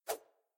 throw1.ogg